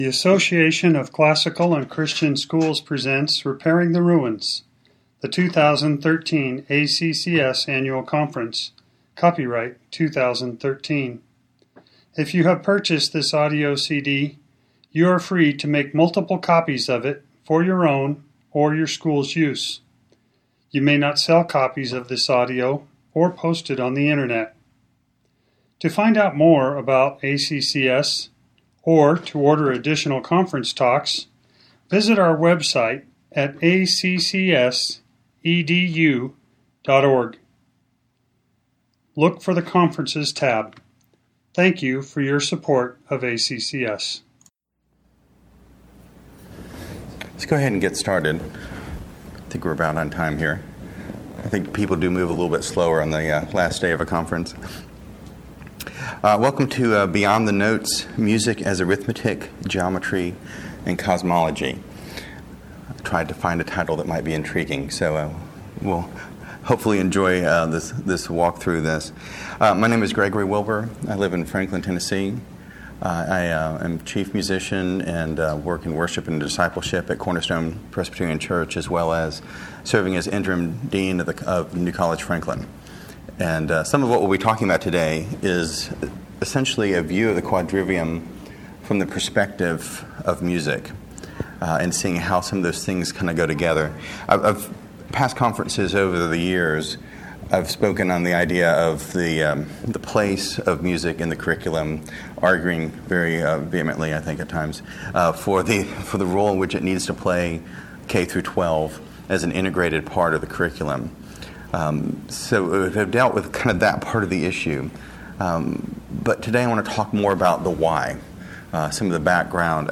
2013 Workshop Talk | 1:00:56 | All Grade Levels, Art & Music, Math
The Association of Classical & Christian Schools presents Repairing the Ruins, the ACCS annual conference, copyright ACCS.